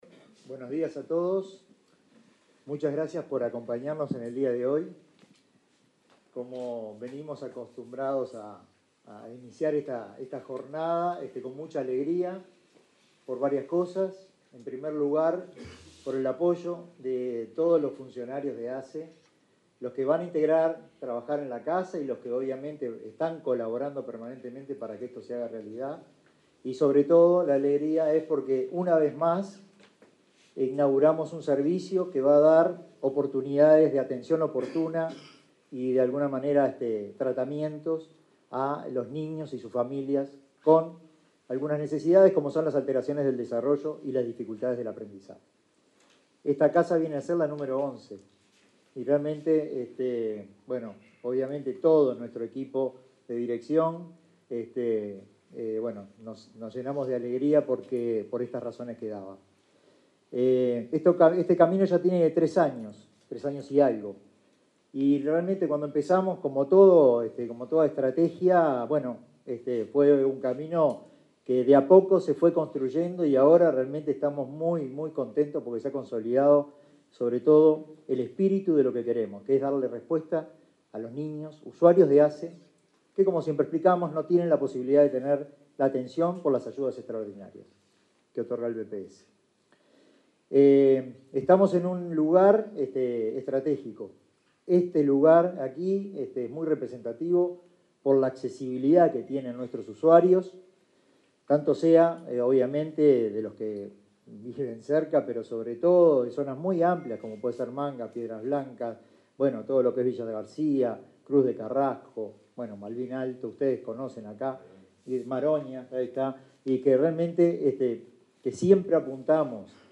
Palabras de autoridades en acto de ASSE
Palabras de autoridades en acto de ASSE 01/07/2024 Compartir Facebook X Copiar enlace WhatsApp LinkedIn El director de Niñez y Adolescencia de la Administración de los Servicios de Salud del Estado (ASSE), Ignacio Ascione, y el presidente de ese prestador, Marcelo Sosa, participaron, el viernes 28 de junio, en la inauguración de la casa del desarrollo de la niñez número once. La nueva infraestructura está ubicada en el barrio La Unión, de Montevideo.